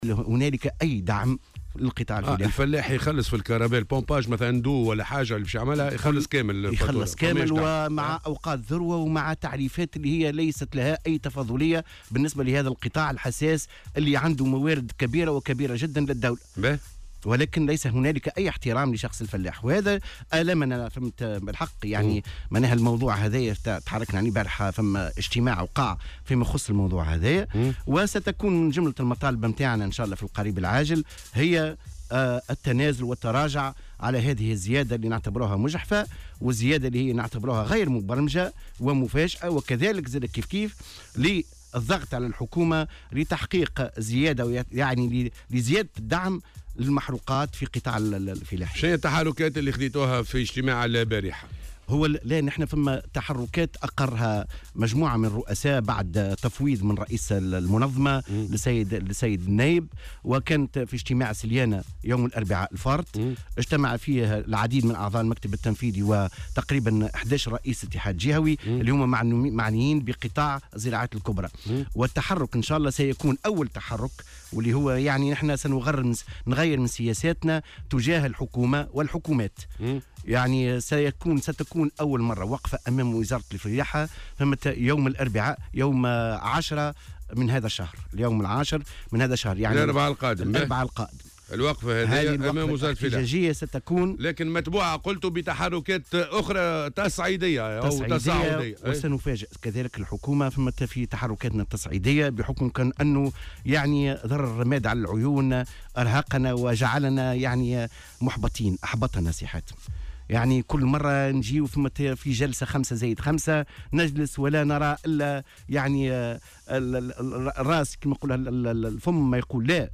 في مداخلة له اليوم في برنامج "صباح الورد" على "الجوهرة أف أم"